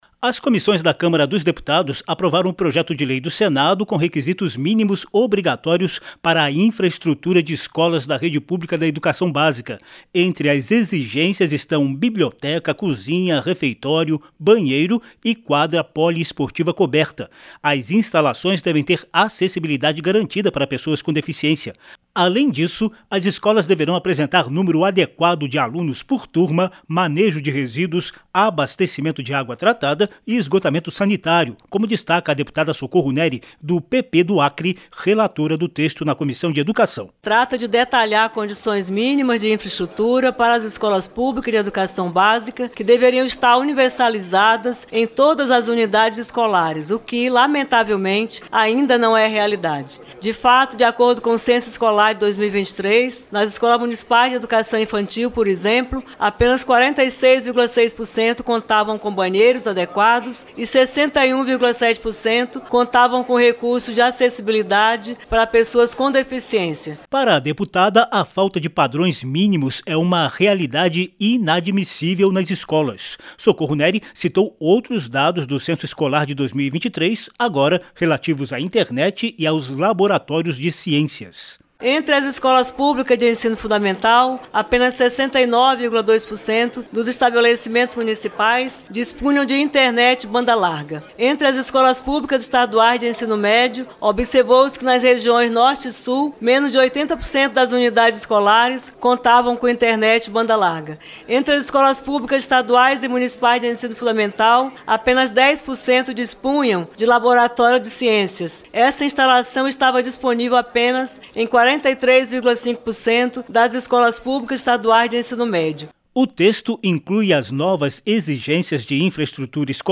CÂMARA APROVA EXIGÊNCIAS DE INFRAESTRUTURA PARA ESCOLAS PÚBLICAS. CONFIRA NA REPORTAGEM